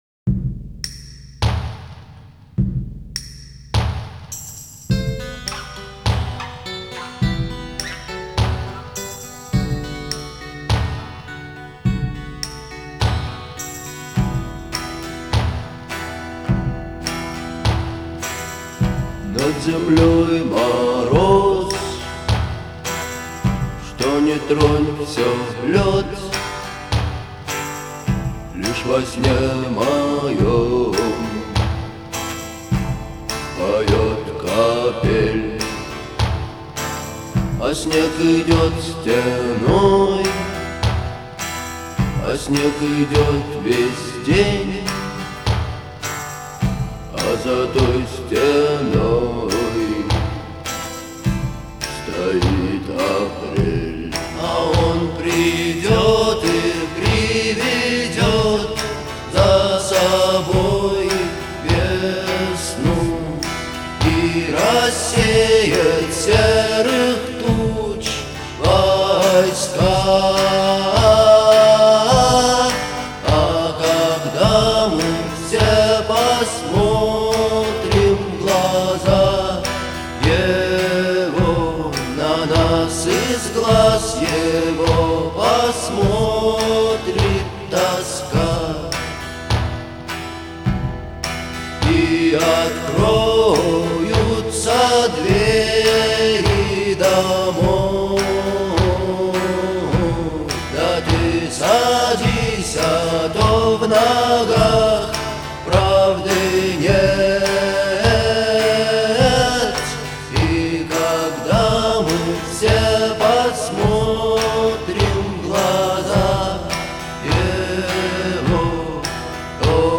Жанр — постпанк с элементами новой волны.